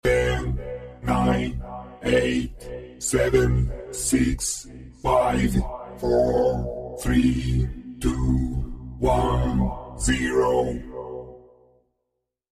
countdown 10 to 0 flipaclip